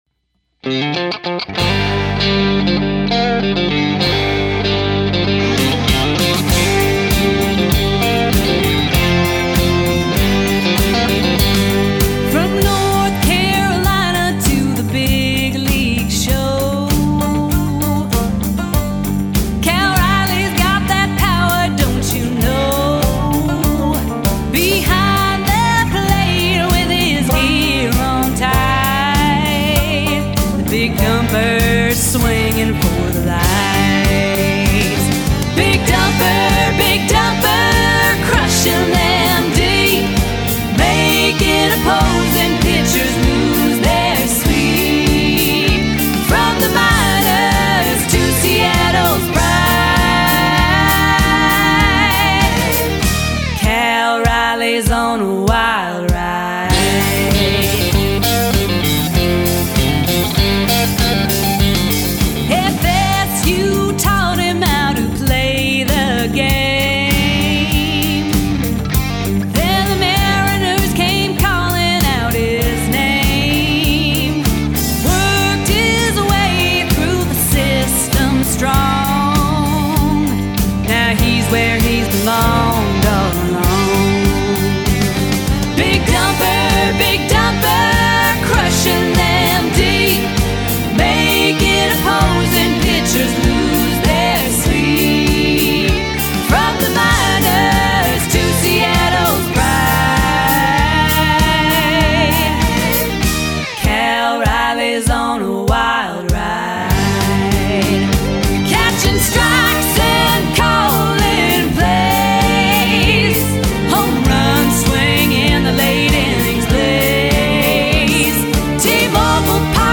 dynamic 5-piece country/rock band